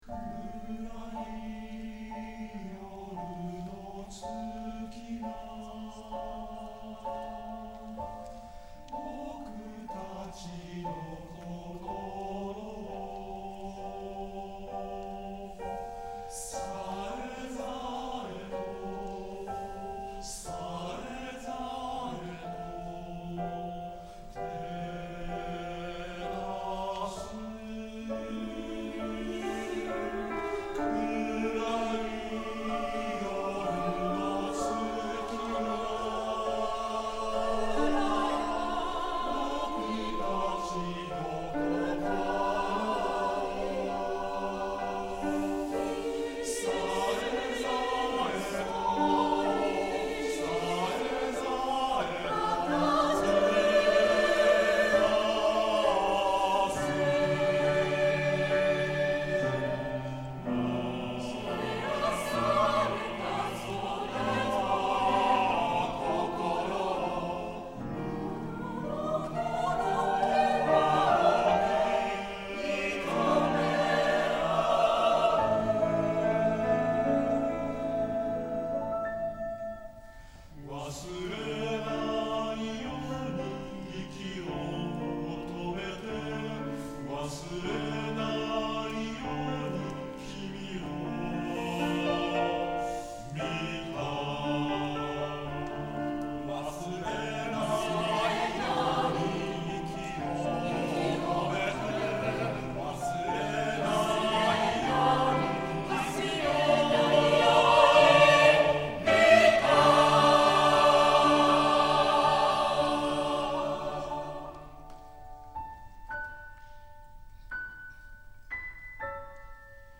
U 月の夜　上田真樹 混声合唱組曲「終わりのない歌」より 4:17 混声合唱団アプリス